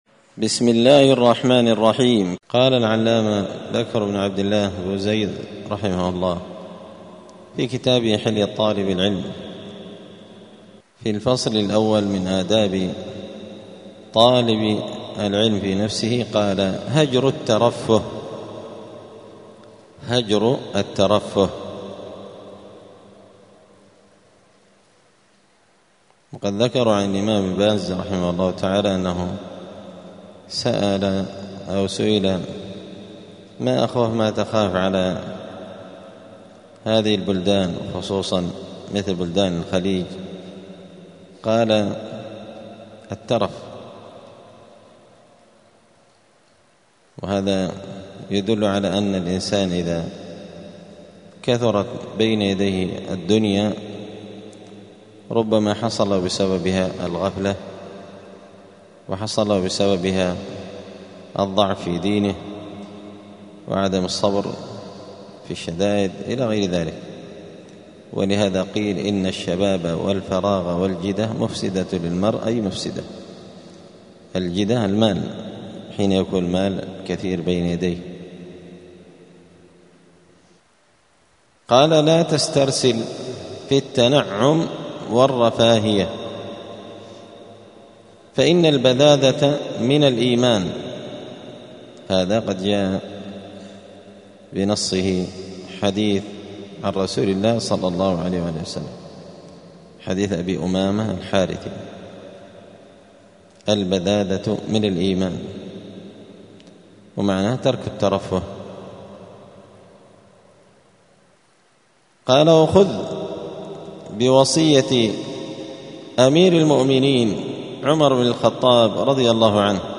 *الدرس الحادي عشر (11) {فصل آداب الطالب في نفسه} (هجر الترفه)*
الأحد 27 ذو القعدة 1446 هــــ | الدروس، حلية طالب العلم، دروس الآداب | شارك بتعليقك | 11 المشاهدات